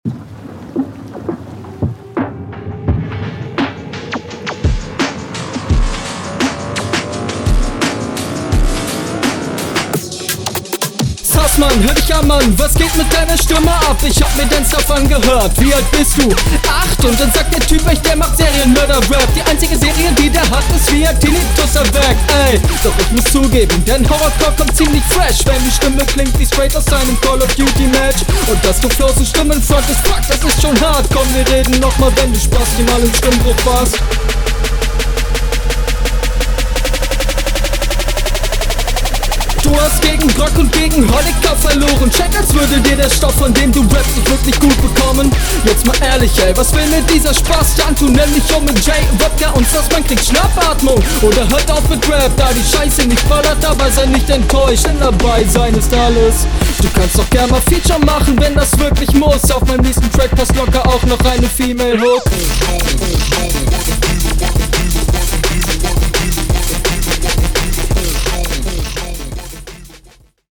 Crazy Beat, sehr kurios.
Puh, der Beat ist.. gewöhnungsbedürftig.